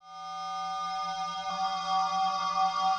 Sizzle - 160 BPM_Insert 1.wav